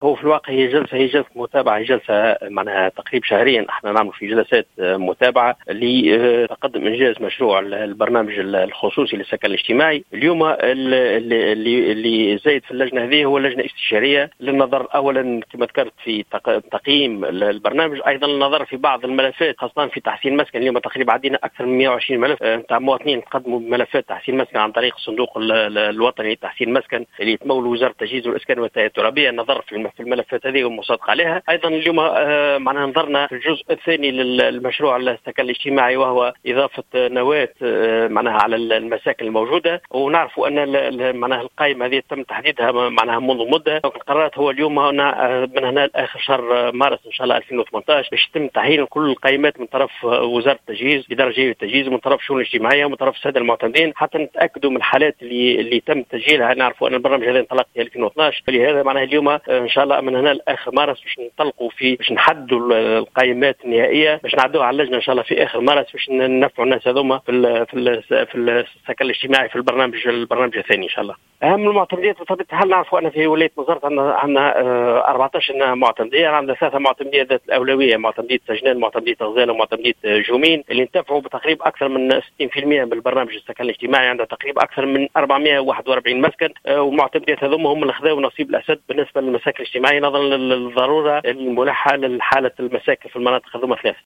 وأضاف في تصريح للجوهرة اف ام أنه سيتم في حدود شهر مارس على اقصى تقدير، الانتهاء من تحديد قائمات المنتفعين بالسكن، مضيفا أن برنامج تحسين السكن يشمل 440 مسكنا.